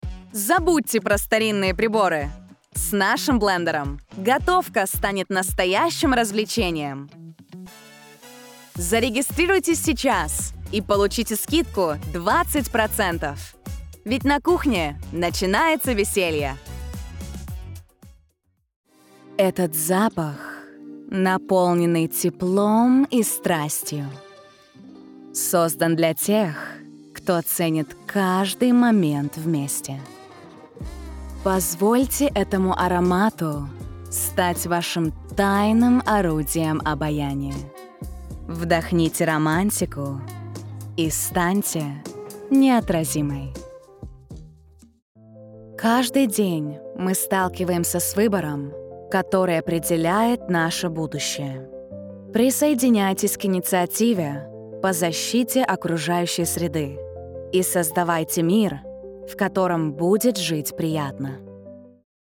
Conversational
Confident
Warm